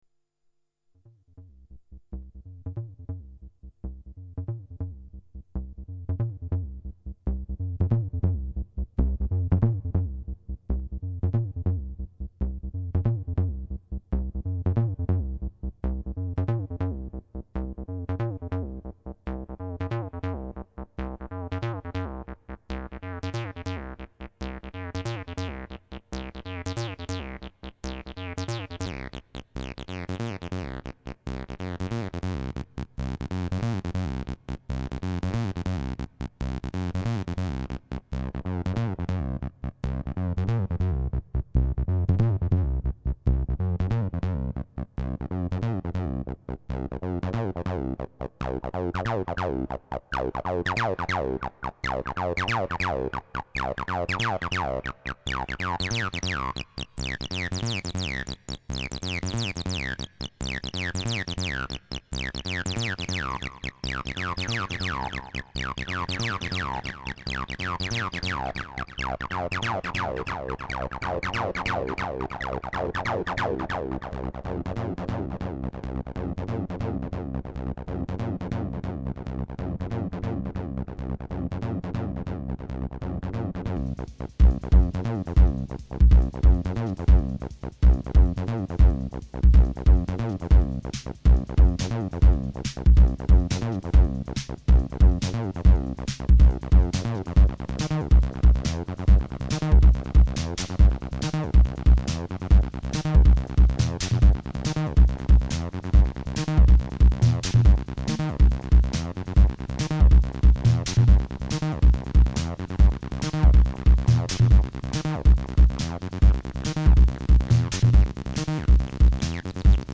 Sound Example 2 - Distorted, with drums from MFB 502